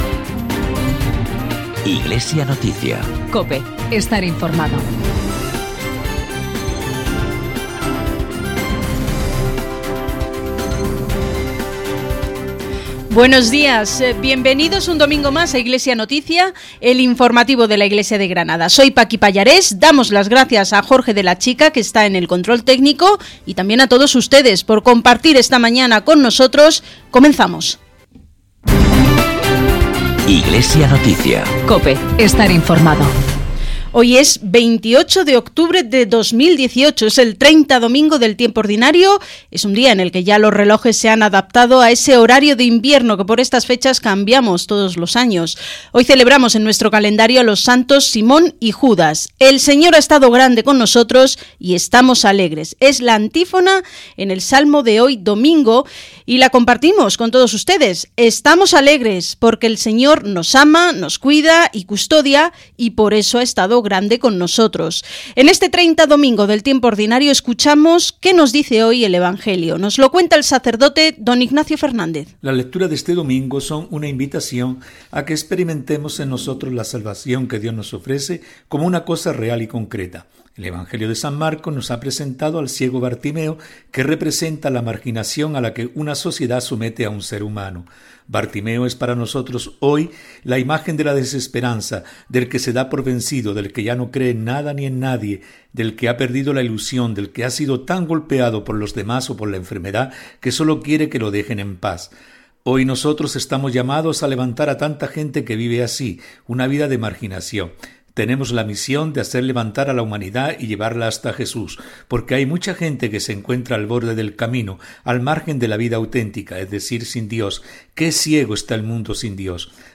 Informativo diocesano emitido el domingo 28 de octubre en COPE Granada, COPE Más Granada y COPE Motril.